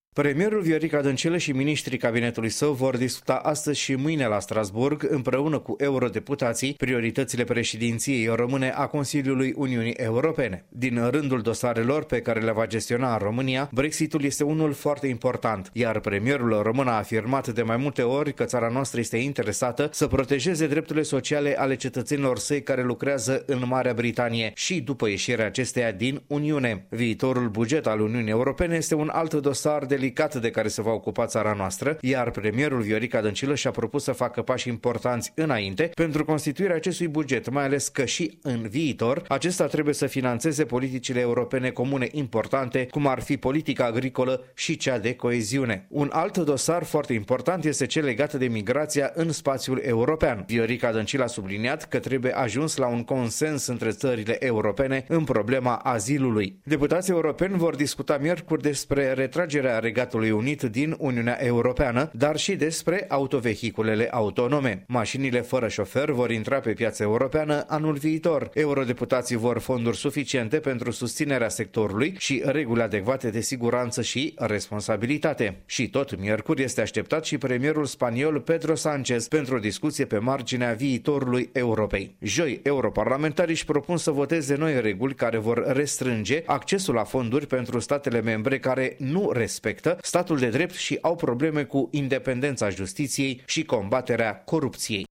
Trimisul nostru la Strasbourg